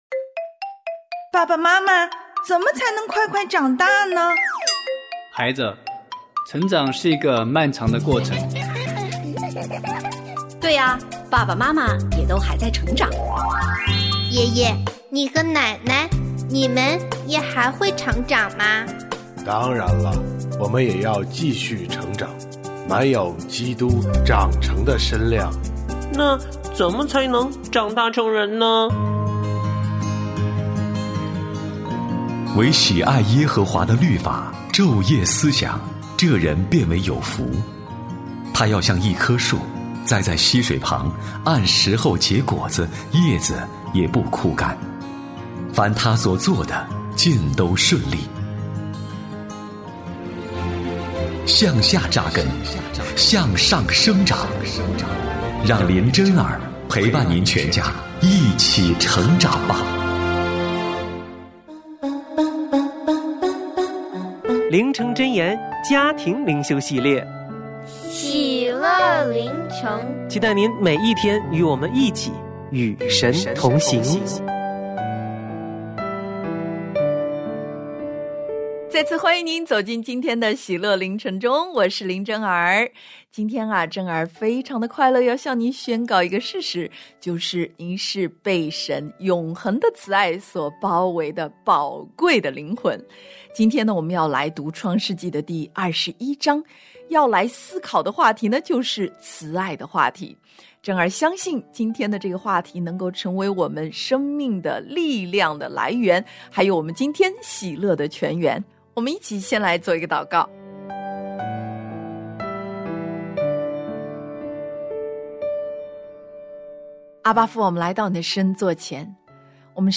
我家剧场：圣经广播剧（146）神奇的斧头；以利沙泄露了亚兰王的密谋